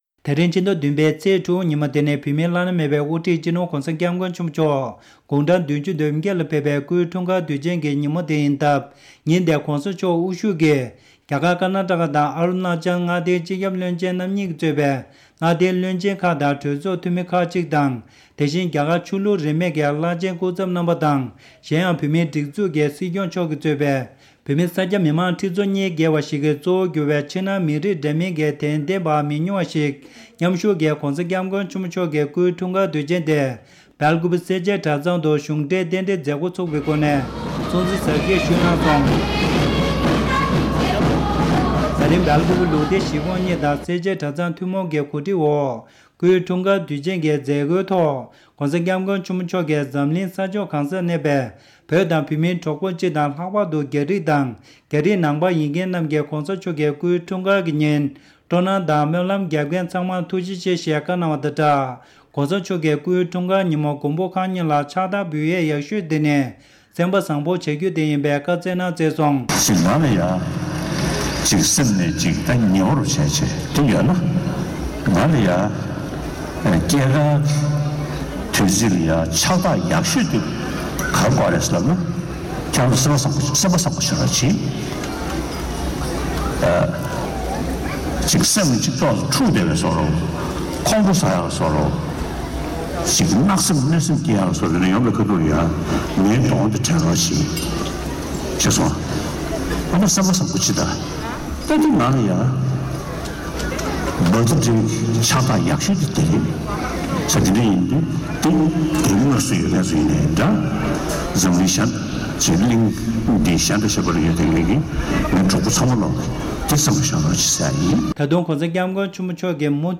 གནས་ཚུལ་ཞིག་ལ་གསན་རོགས་ཞུ༎